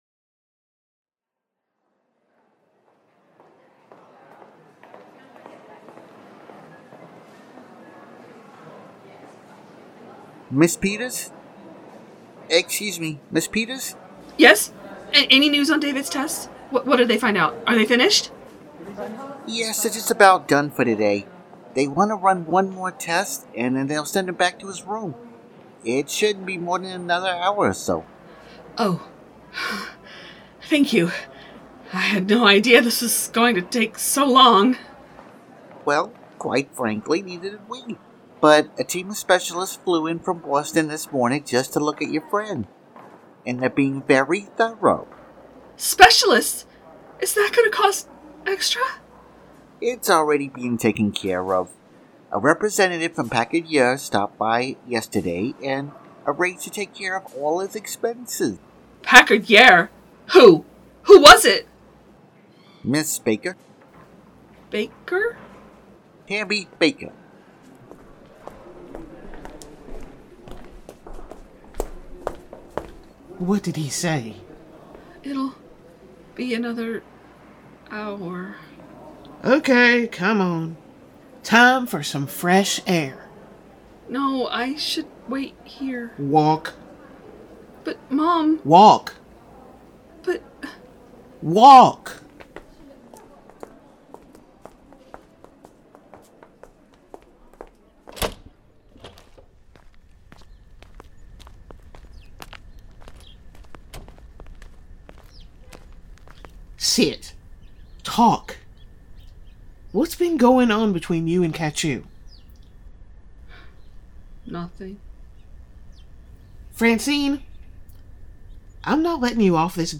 Strangers In Paradise – The Audio Drama – Book 8 – My Other Life – Episode 10 – Two True Freaks
The Ocadecagonagon Theater Group